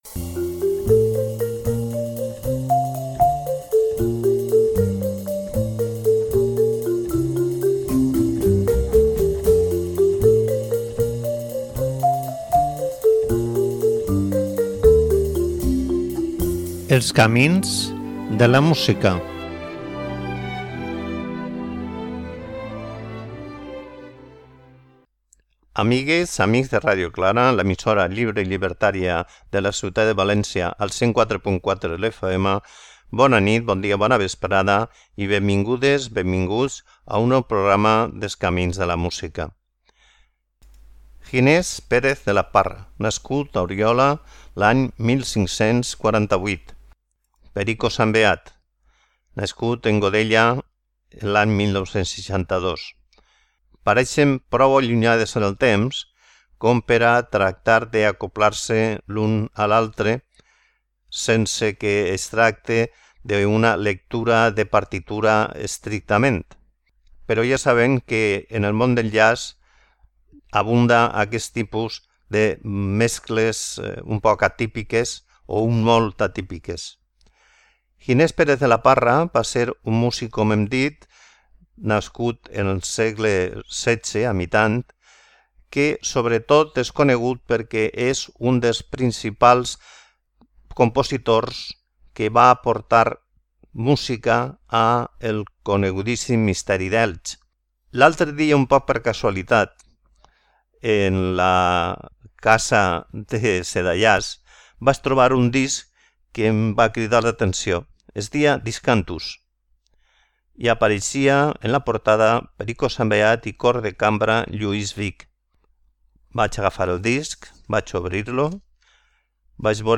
gravat en directe
Una música per escoltar amb calma i en silenci.